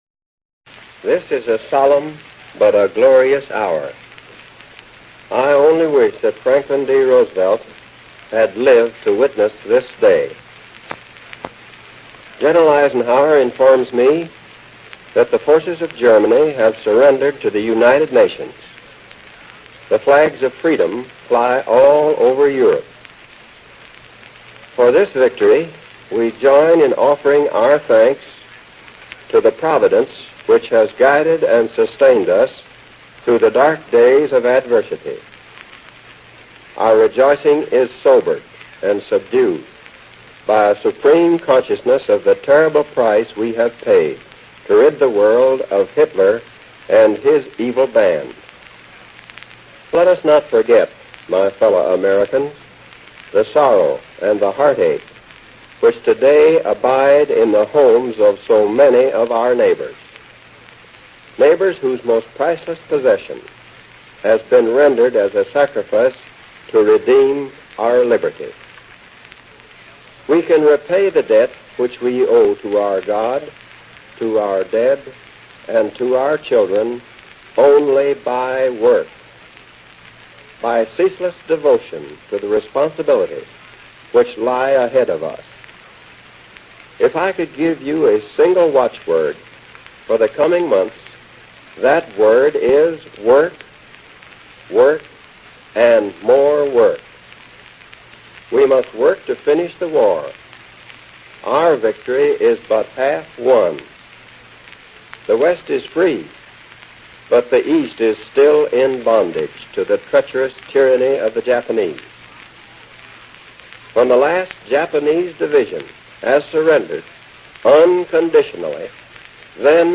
Truman's Victory In Europe Speech
Tags: History Presidents Of the U. S. President Harry S. Truman Speeches